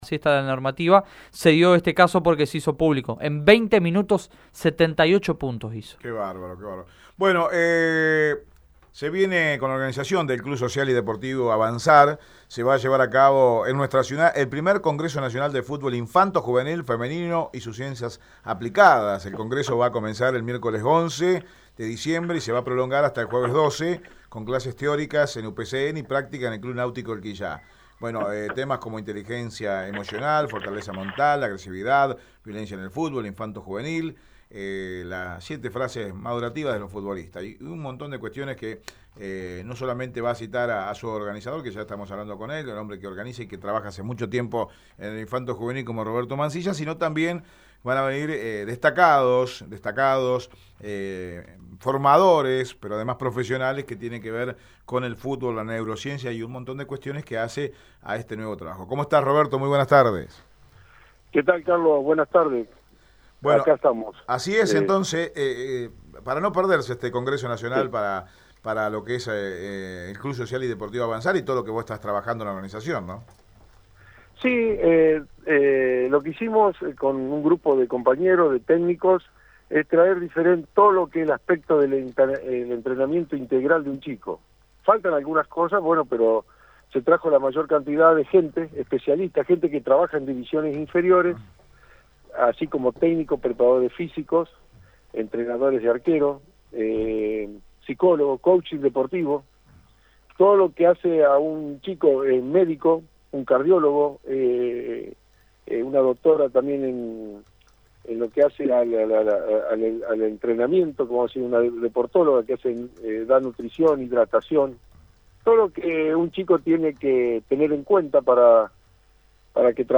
En Radio Eme Deportivo dialogamos